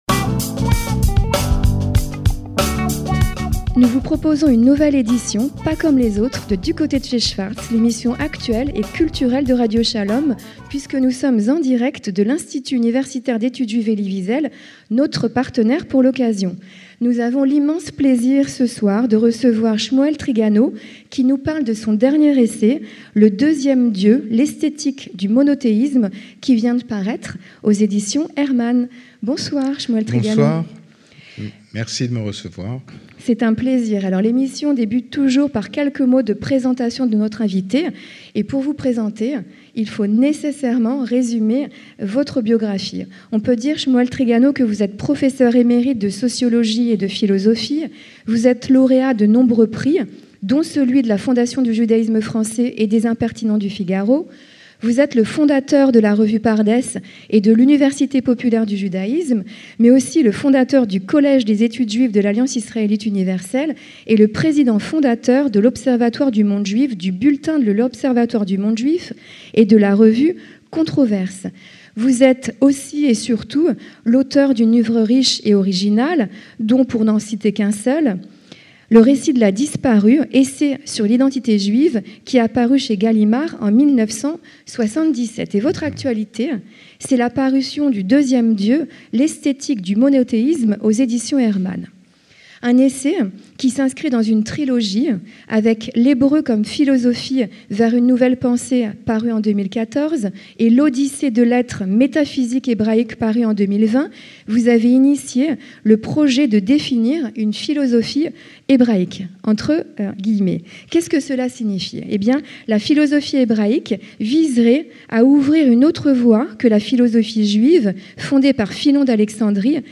En direct de l’Institut Elie Wiesel, le Pr.